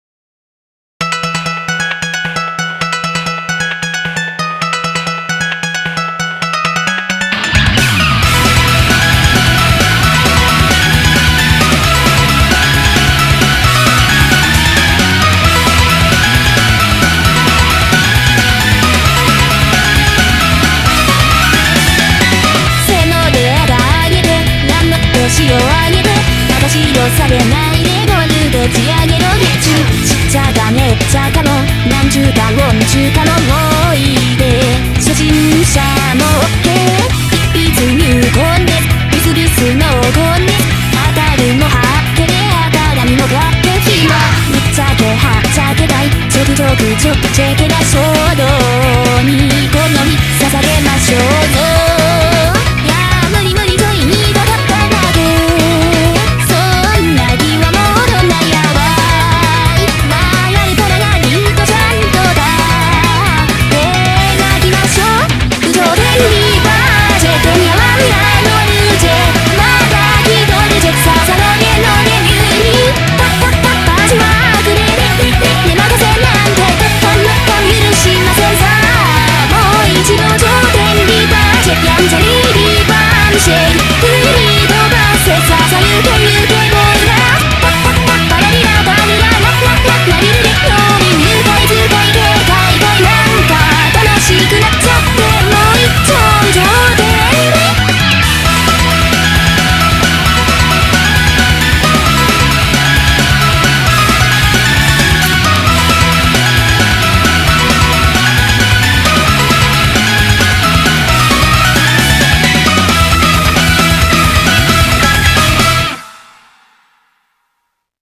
Audio QualityPerfect (High Quality)
BPM 266